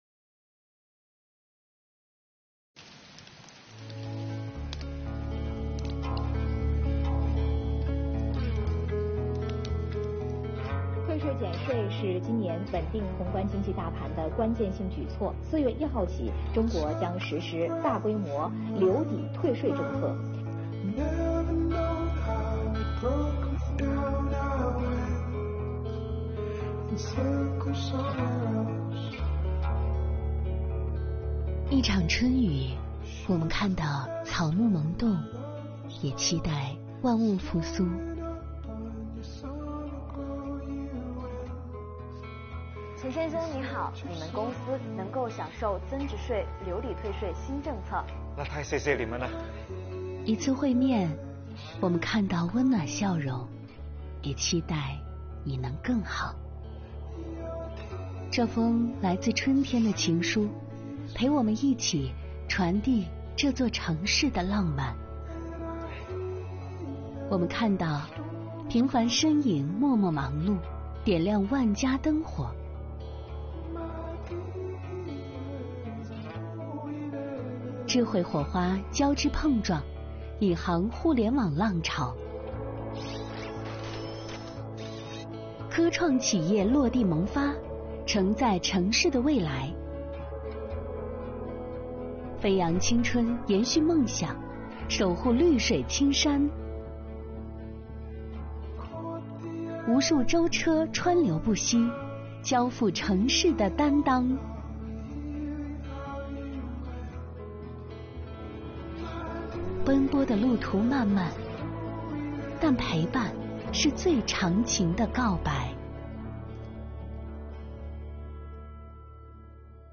税务人员给您一封春雨情书，为您唱一首助力恋歌，伴您一路成长。春雨润苗，助力小微企业重拾生机。